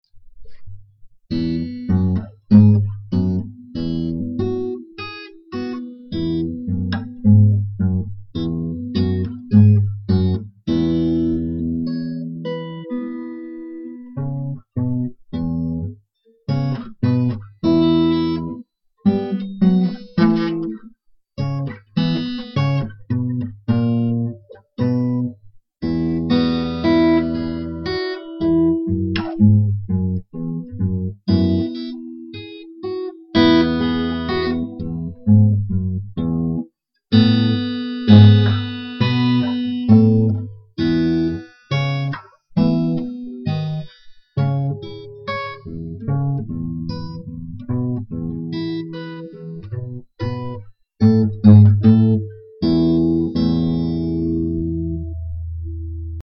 Tags: mandolin jam music